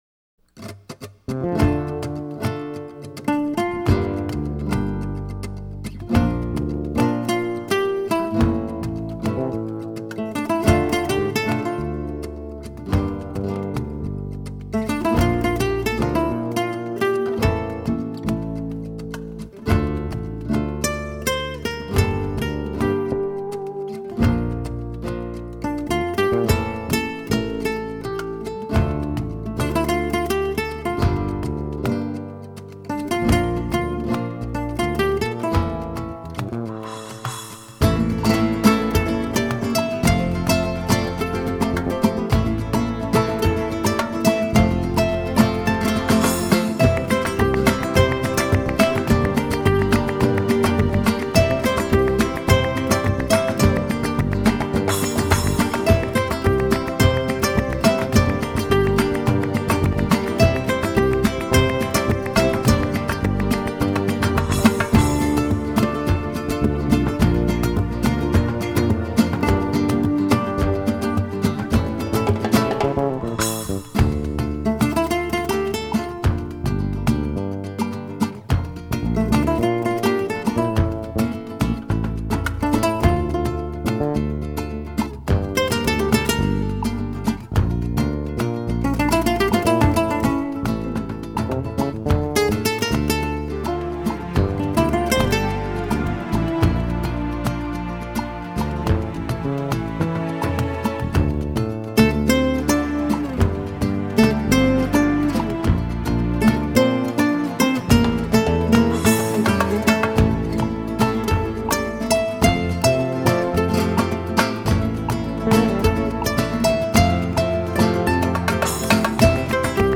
guitaist